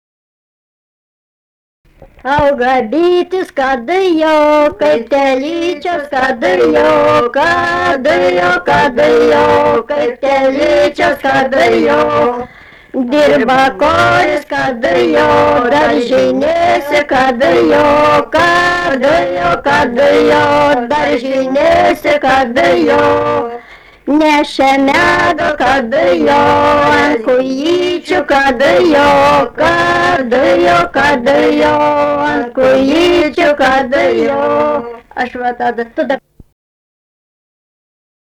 sutartinė
Venslaviškiai
vokalinis